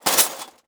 TOOL_Toolbox_Open_mono.wav